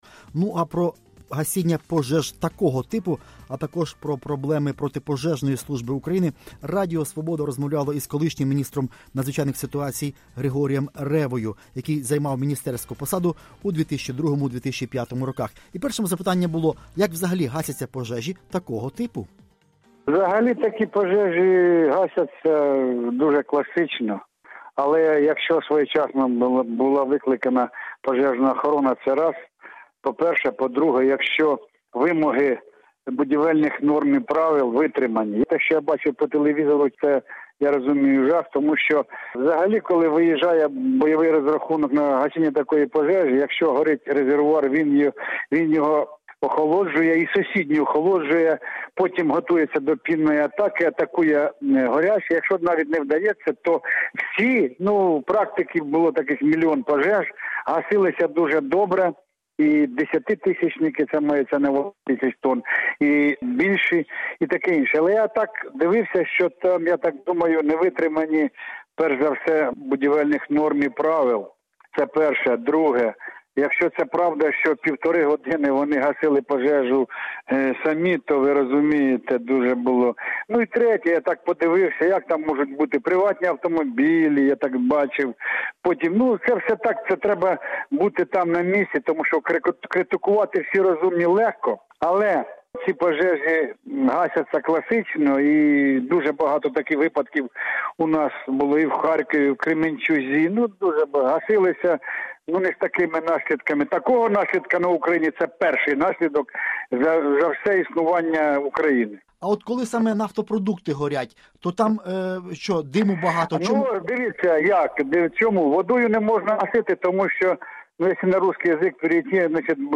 Про гасіння пожеж такого типу, а також проблеми пожежної служби України Радіо Свобода розмовляло з колишнім міністром надзвичайних ситуацій Григорієм Ревою, який займав міністерську посаду в 2002-2005 роках.